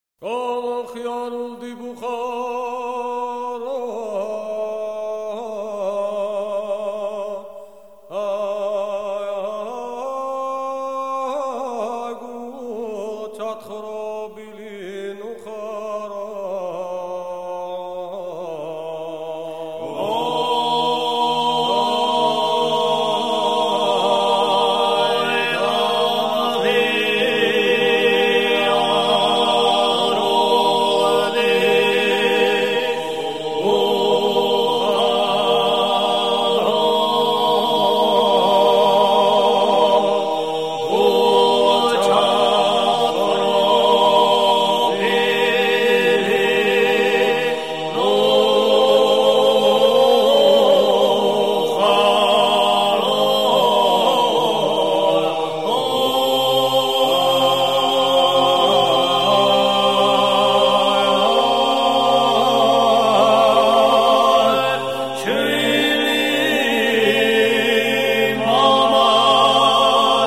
Les polyphonies
On distingue aujourd'hui trois grands types de polyphonie, chacune originaire d'une région particulière : une polyphonie à trois voix où les chants sont alignés verticalement en accords selon un rythme complexe, qui réclame bequcoup d'ouverture d'esprit à l'écoute, typique de la Svanétie ; une polyphonie pouvant compter jusqu'à sept ou huit voix rythmiquement et mélodiquement très indépendantes, représentative de la région de Gourie (extrême ouest, au bord de la mer noire) ; enfin, la polyphonie de Kakhétie, où une ou deux voix de ténor dialoguent au-dessus d'une basse continue.
Le choeur de Tsinandali
Est représentatif des polyphonies telles qu'on les chante en Kakhétie. A noter qu'il s'agit d'un choeur amateur, composé exclusivement d'amateurs de la bonne chère.